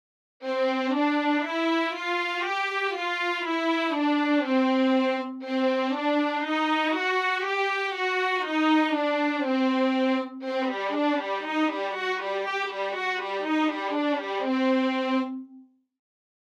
This is the Violin I dry ensemble with no other effects.
I am trying to get the sound of a bowed legato, but to me it still sounds like a bow change between each note.